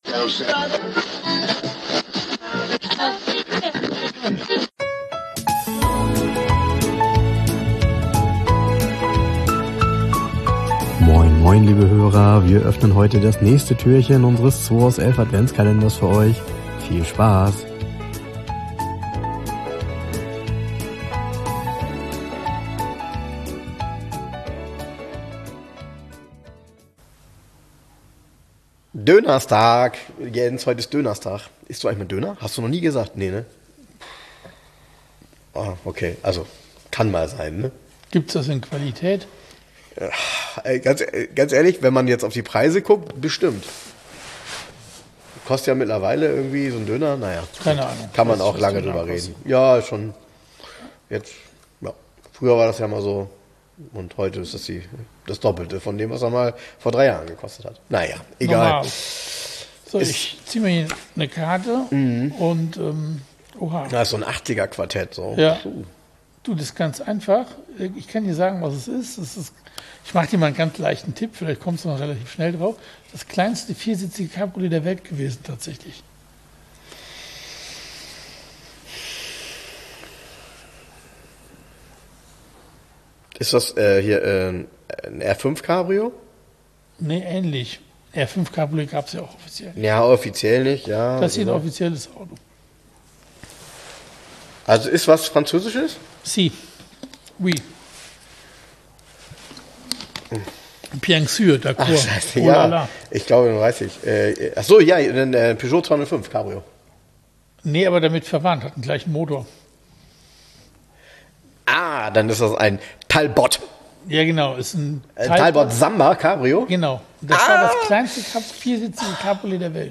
aufgenommen in der Garage 11 in Hamburg.